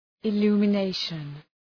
Shkrimi fonetik{ı,lu:mə’neıʃən}